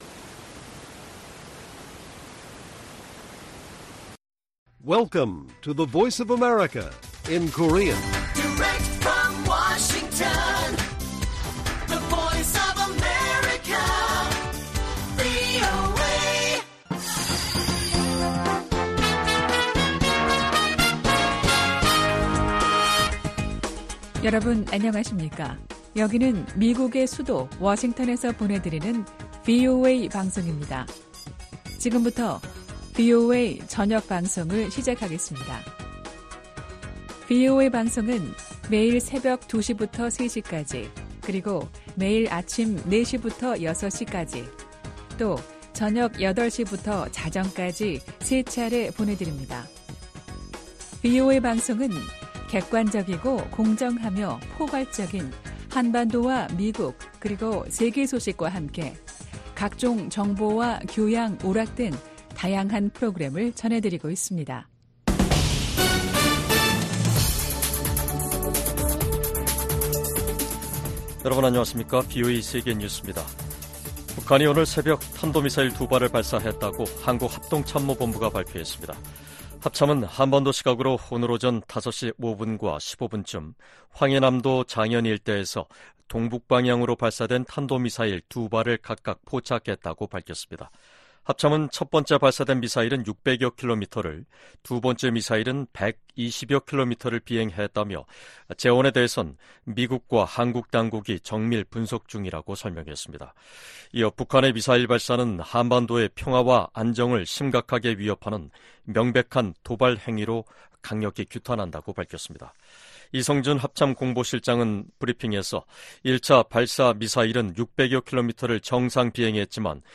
VOA 한국어 간판 뉴스 프로그램 '뉴스 투데이', 2024년 7월 1일 1부 방송입니다. 북한이 한반도 시각 1일 탄도미사일 2발을 발사했다고 한국 합동참모본부가 밝혔습니다. 유엔 안보리가 공식 회의를 열고 북한과 러시아 간 무기 거래 문제를 논의했습니다. 북러 무기 거래 정황을 노출했던 라진항에서 또다시 대형 선박이 발견됐습니다.